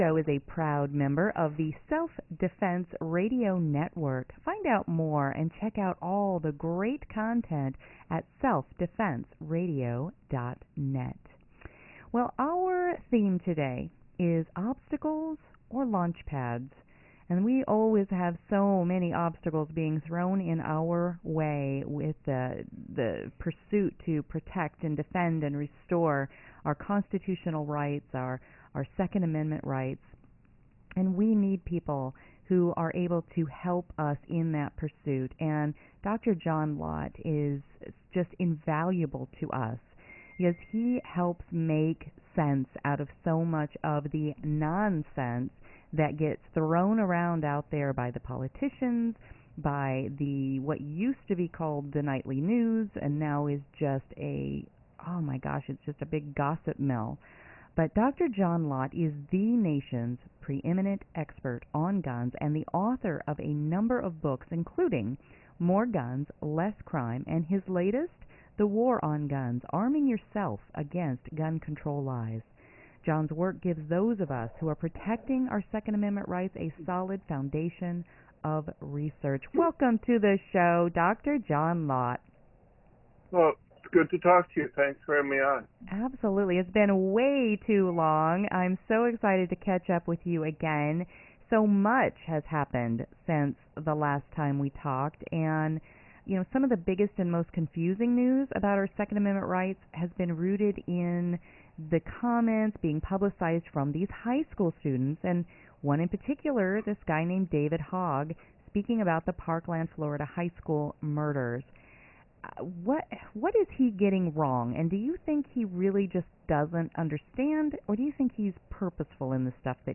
Dr. John Lott talked to radio hosts